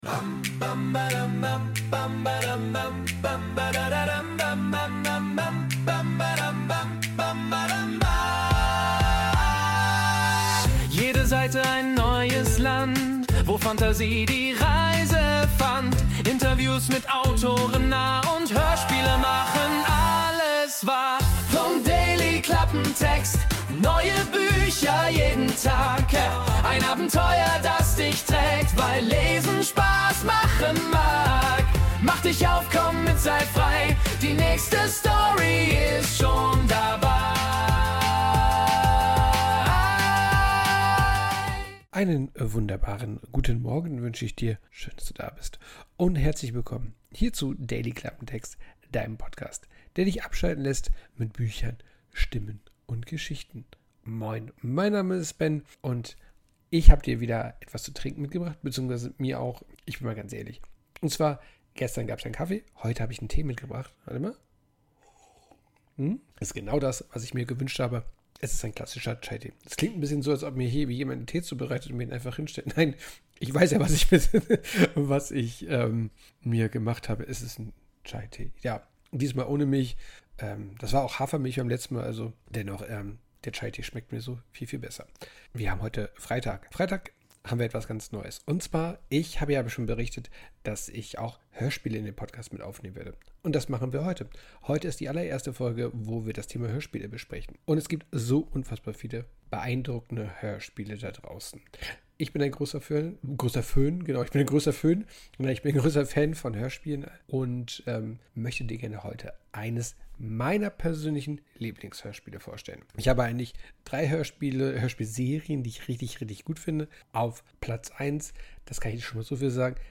Intromusik: Wurde mit der KI Music AI erstellt.